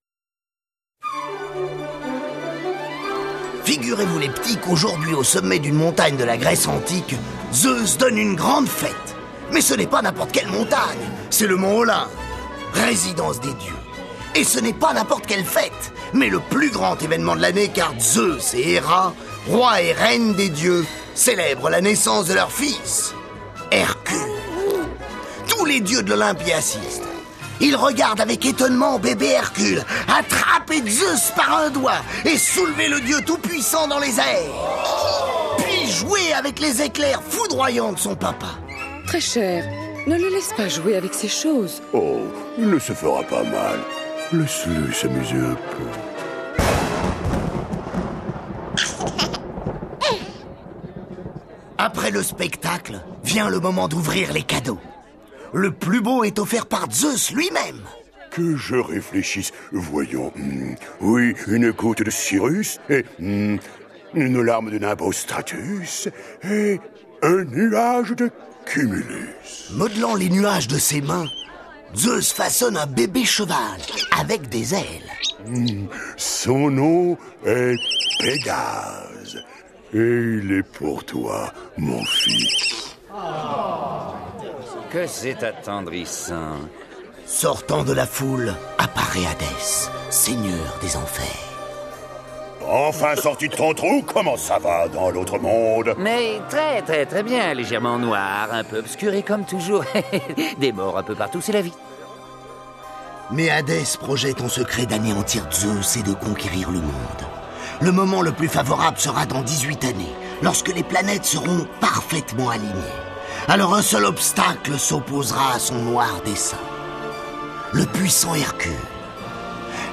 copyparty tmp/au/vfs/trds1_enc/kids/Disney DL/Livre audio - 21 histoires - Walt Disney - L'histoire et les dialogues (MP3.128Kbps)